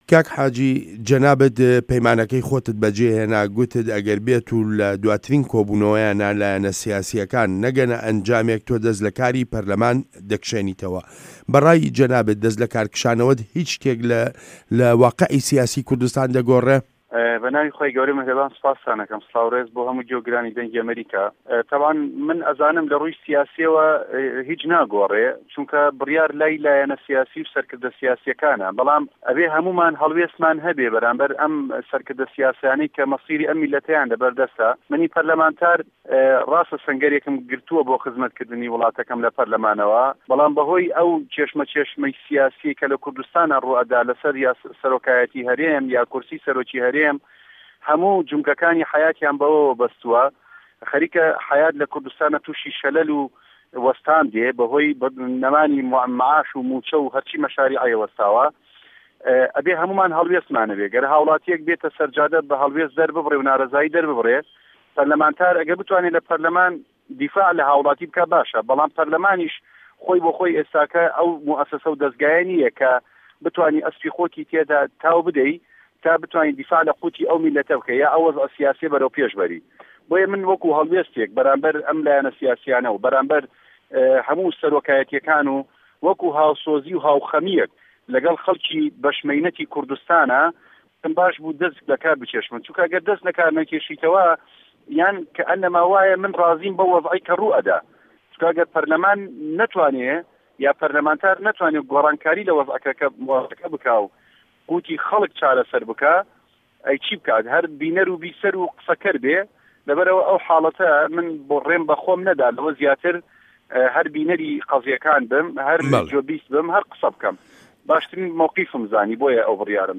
وتووێژ لەگەڵ حاجی کاروان